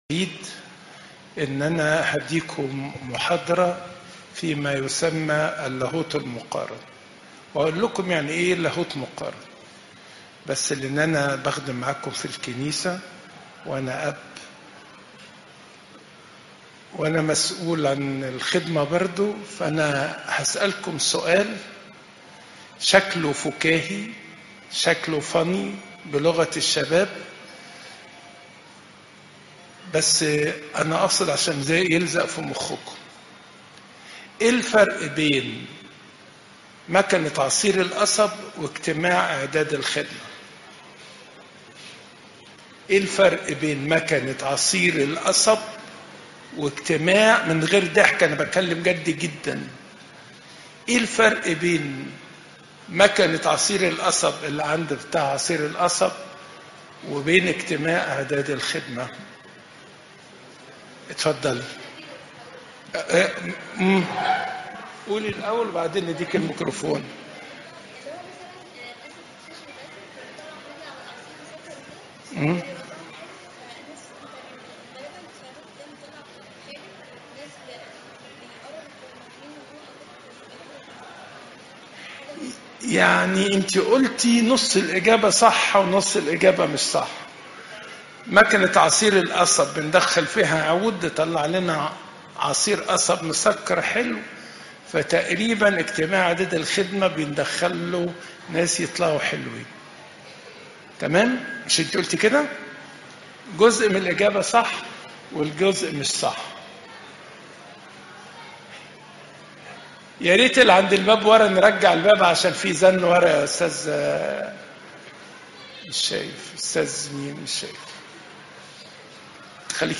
عظات المناسبات